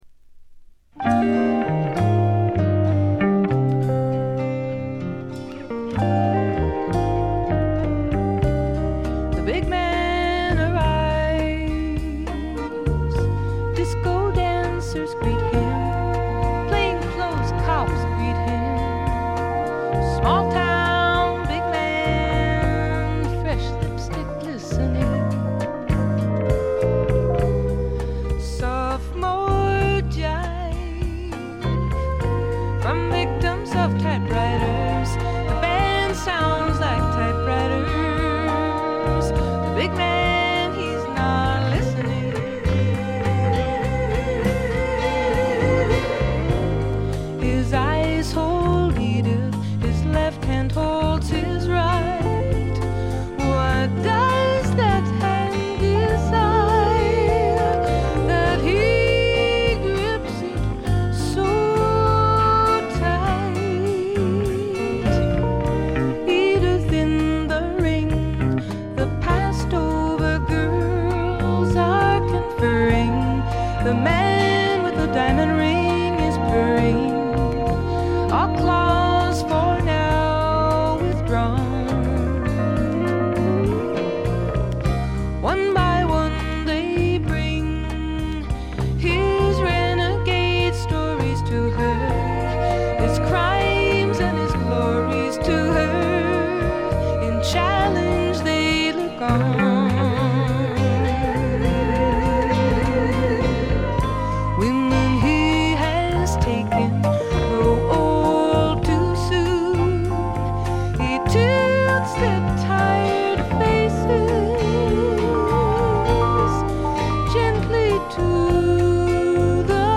ディスク:部分試聴ですがほとんどノイズ感無し。極めて良好に鑑賞できると思います。実際の音源を参考にしてください。
ここからが本格的なジャズ／フュージョン路線ということでフォーキーぽさは完全になくなりました。
女性シンガーソングライター名作。
試聴曲は現品からの取り込み音源です。